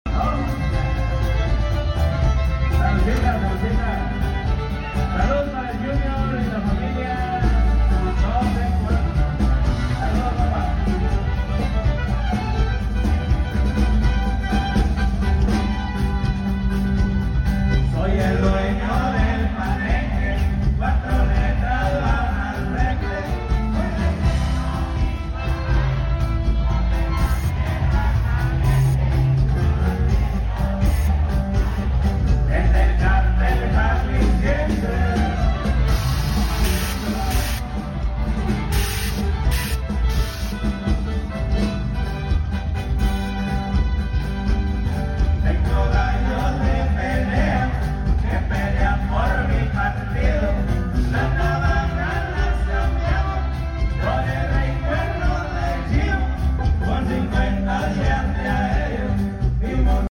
Cierre de carnaval en Copandaro